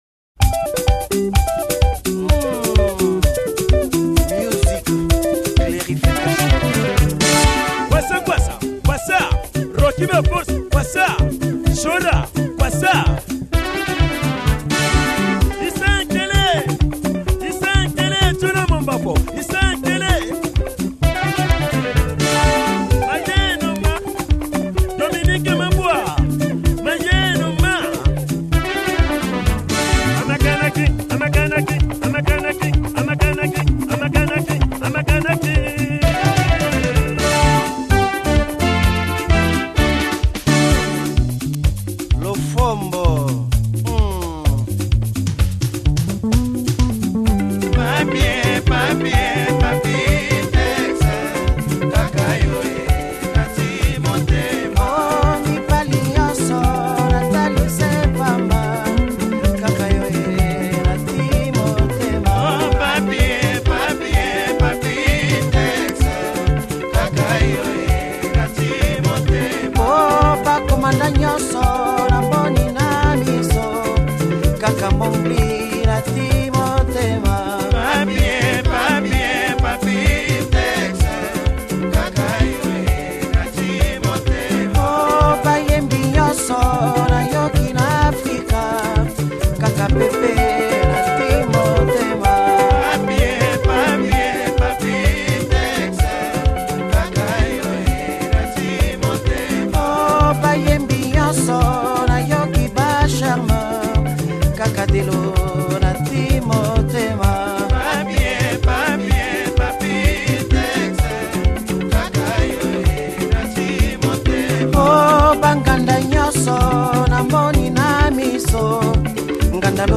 AudioCongoZilipendwa